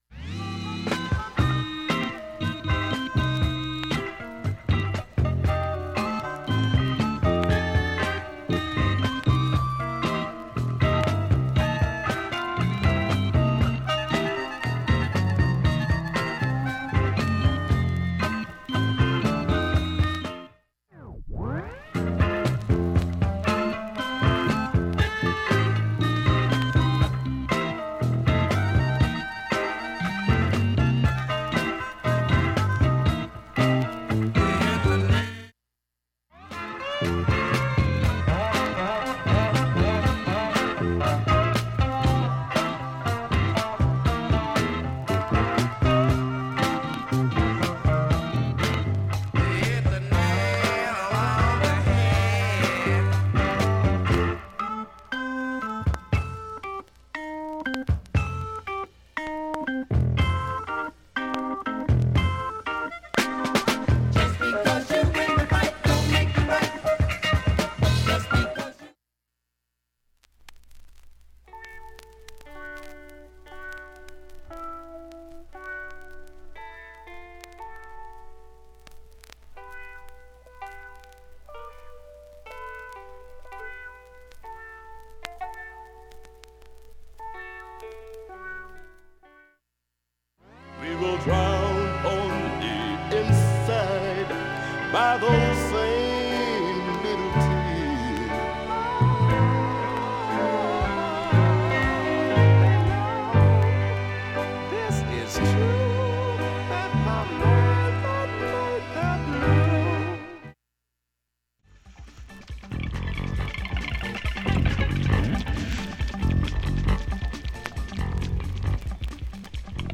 盤面きれいです音質良好全曲試聴済み。